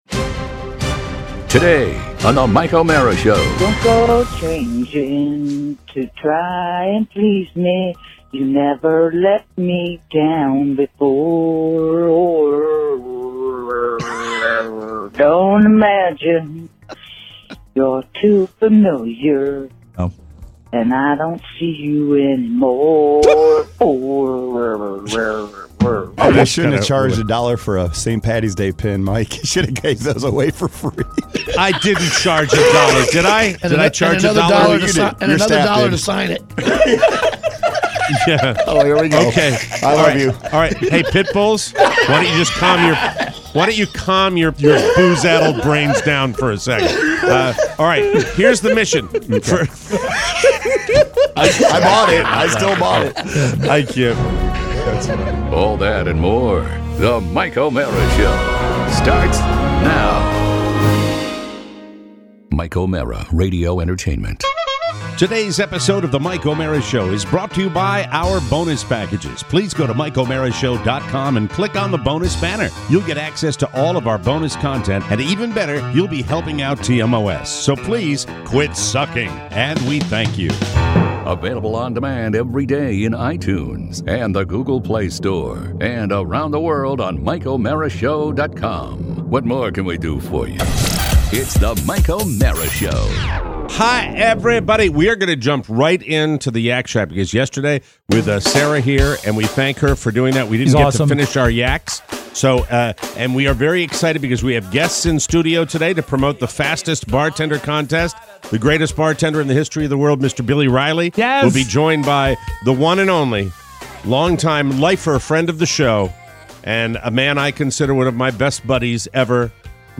We are joined in studio today by two legendary mixologists